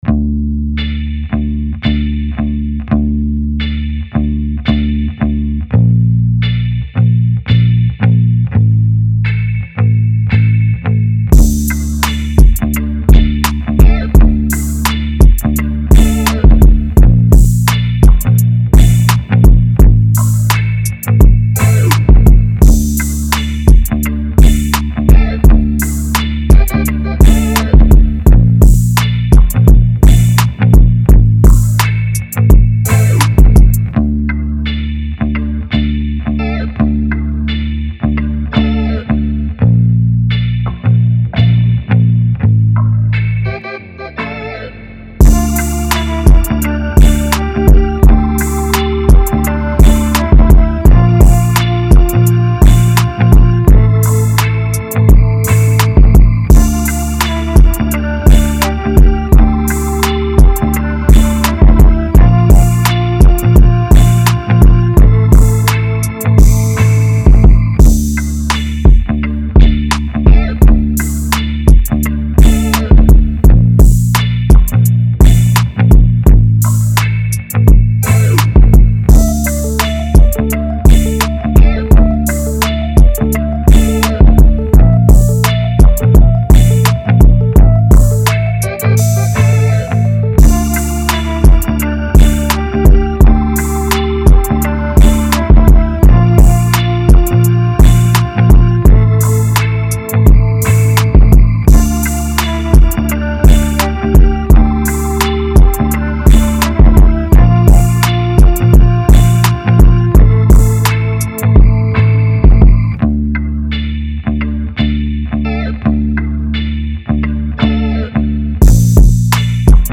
Category Rap